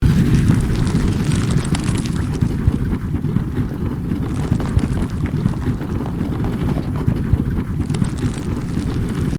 Rolling Avalanche
SFX
yt_stzDqMa6-qk_rolling_avalanche.mp3